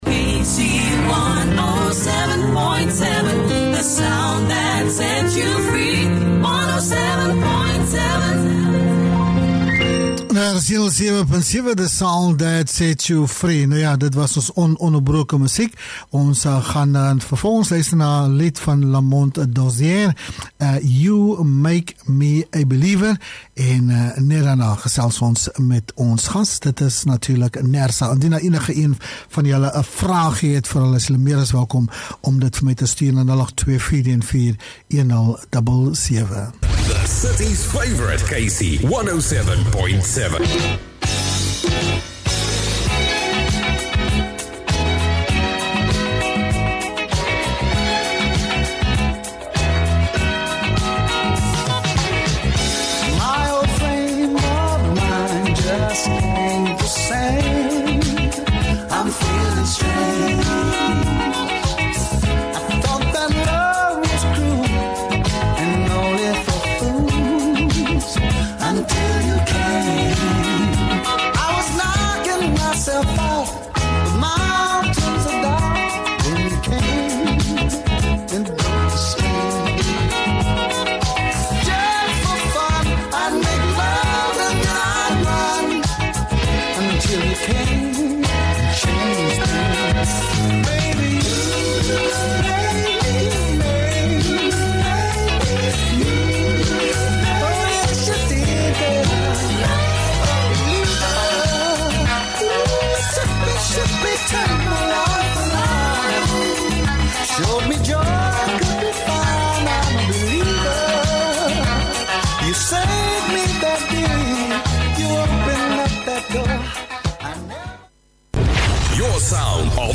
6 Oct Nersa Interview